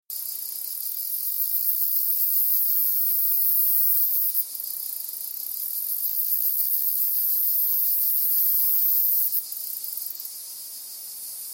Dimissalna dimissa (Hagen, 1856) - la Cigale des Balkans
La cymbalisation d’appel de Dimissalna dimissa est émise à des fréquences élevées, peu audibles et proches de celles des Cicadetta, les motifs variés constituent un chant très caractéristique et aisé à identifier.
[mp3] Dimissalna enregistrée à Flassans-sur-Issole (Var) en juillet 2010 avec des cymbalisations de Cigale grise Cicada orni en fond.
Les cymbalisations sont proches de la Cigale des collines Cicadetta brevipennis quoique plus longues (de l'ordre de 6 seconde pour Dimissalna, contre 4 pour la Cicadetta) et elles sont modulées comme chez la Cigale plébéienne Lyristes plebejus.